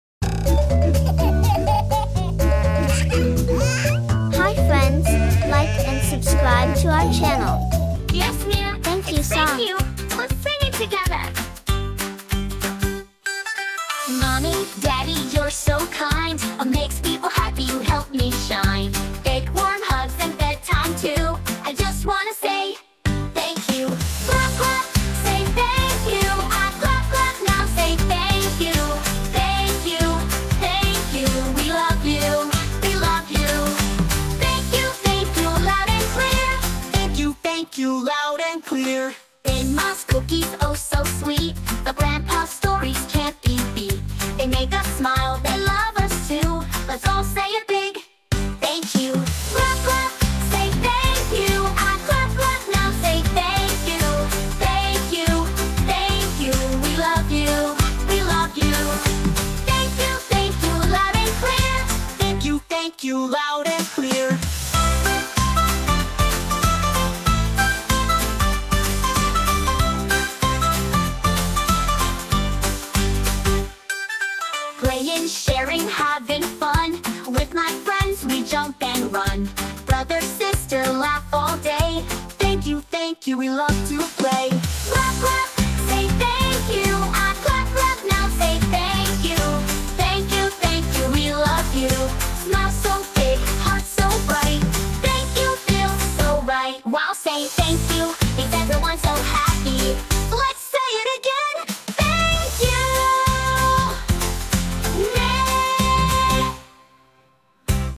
joyful, clapping, and happy song
Designed for ages 2–10, this catchy tune makes kindness fun!